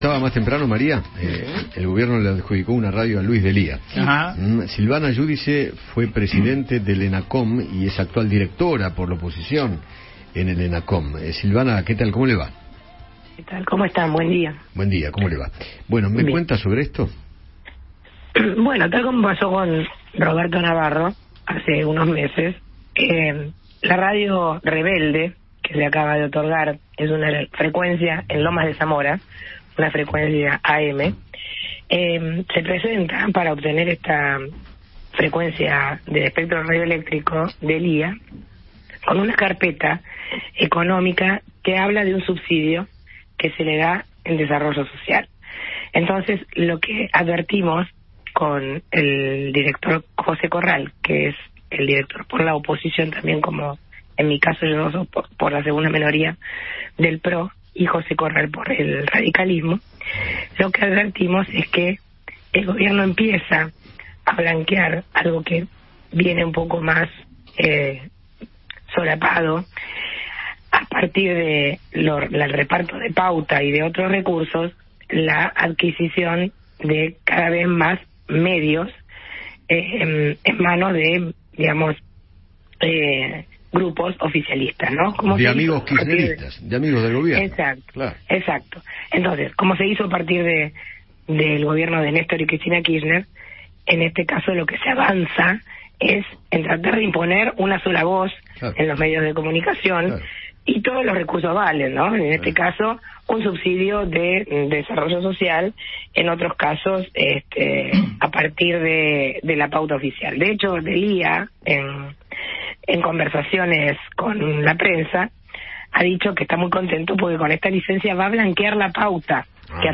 Silvana Giudici, directora de la oposición parlamentaria del ENACOM, dialogó con Eduardo Feinmann sobre la radio que le otorgó el Ente Nacional de Comunicaciones a la cooperativa Radio Rebelde, que preside Luis D’Elía.